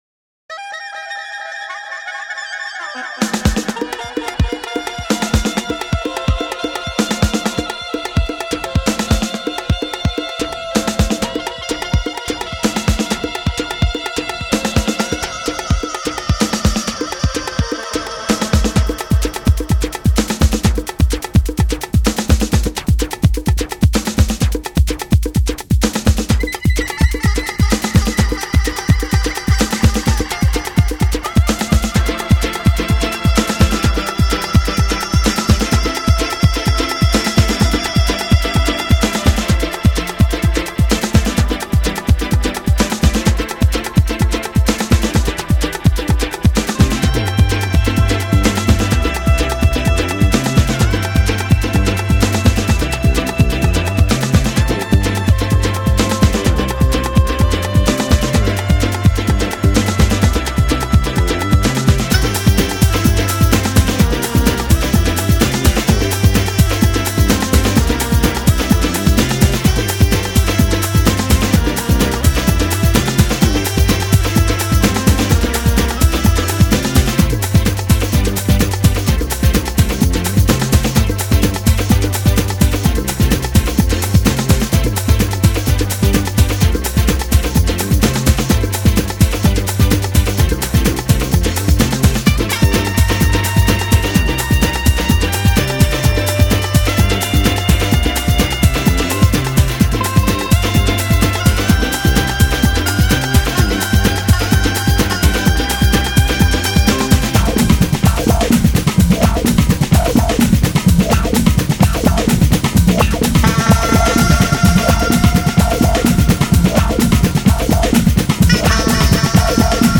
electronic group